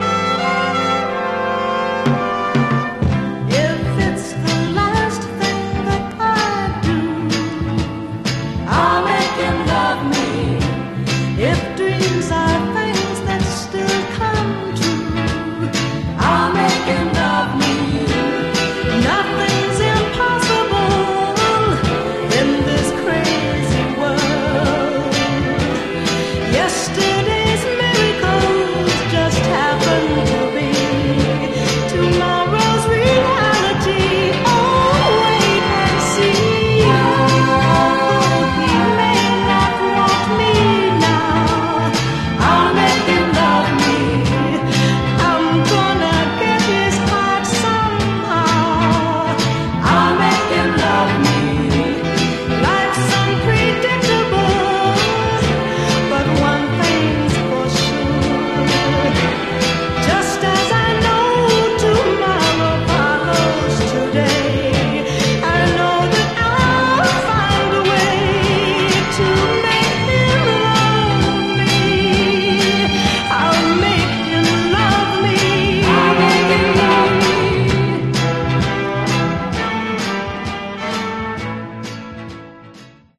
Genre: Other Northern Soul